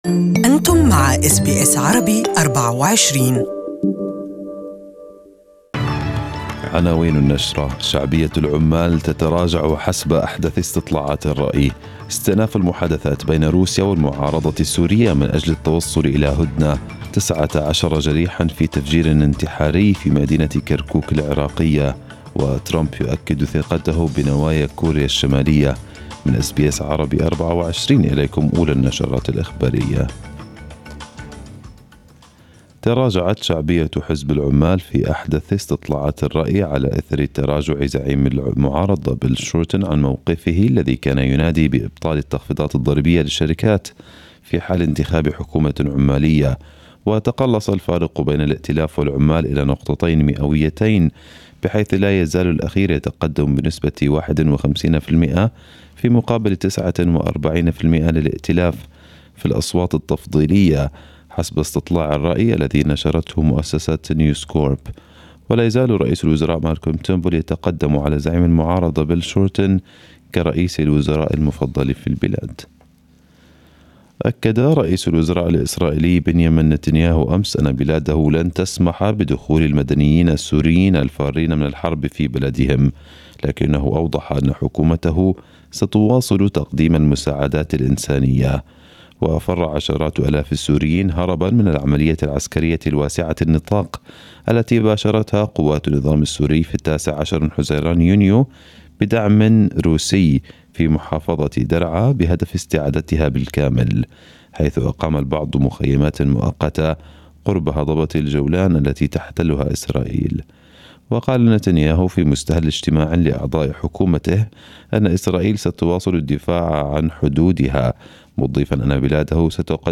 Arabic News bulletin 02/07/2018